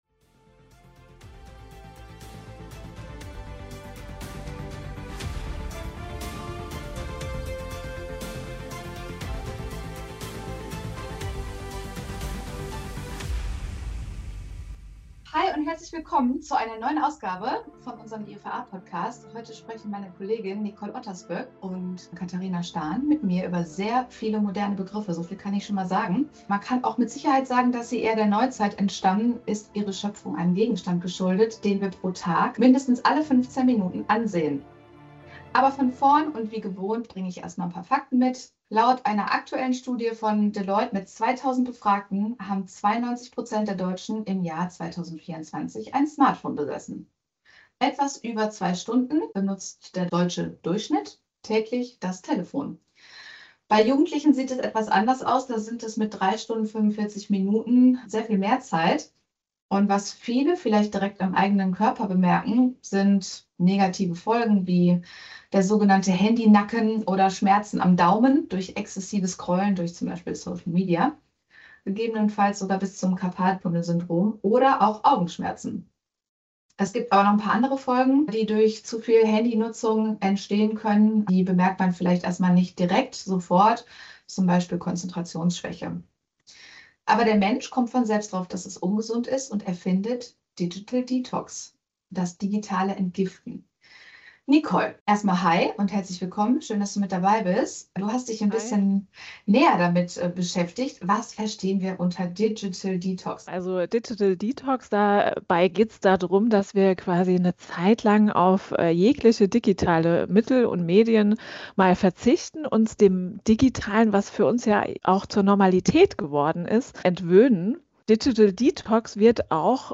In dieser Folge sprechen meine Kolleginnen und ich über einen Gegenstand, dessen Benutzung für uns nützlich wie auch potenziell krankmachend sein kann: Das Smartphone und andere Medien, die mit dem Versprechen locken, ganz viel in kurzer Zeit am besten gleichzeitig erledigen zu können.